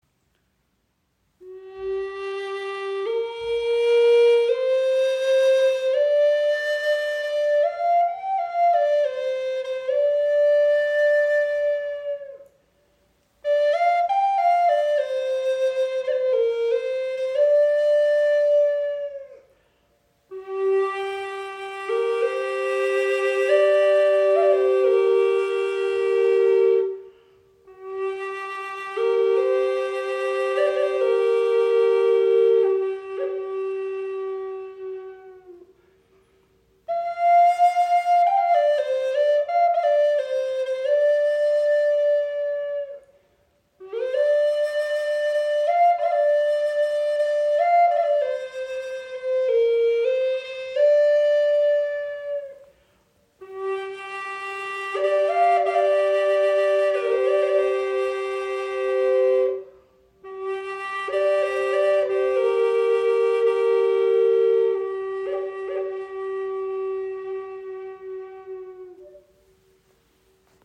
Doppelflöte in G im Raven-Spirit WebShop • Raven Spirit
Klangbeispiel
Sie schenkt Dir ein wundervolles Fibrato, kann als Soloinstrument gespielt werden oder als weiche Untermahlung Deiner Musik.